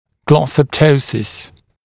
[ˌglɔsəp’təusɪs][ˌглосэп’тоусис]глоссоптоз (недоразвитие и западение языка)